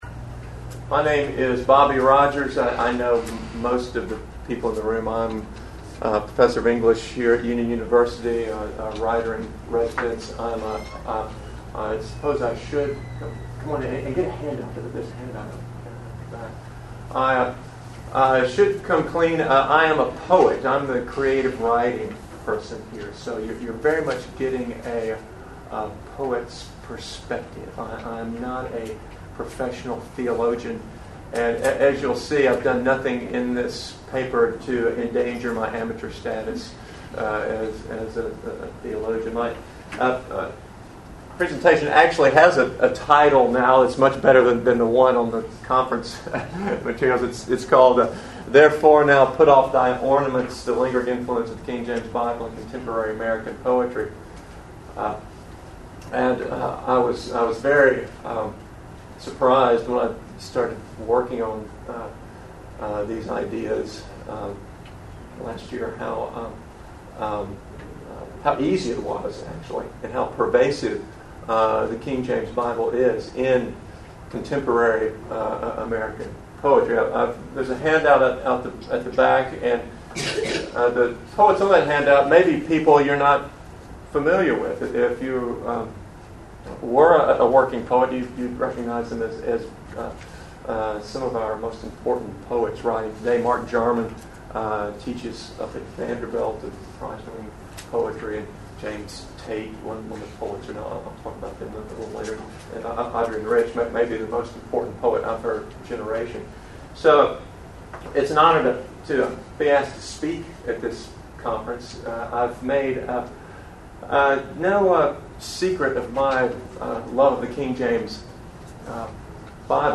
KJV400 Festival
Address: The Presence in Contemporary American Poetry of the Poetic Strategies of the King James Bible Recording Date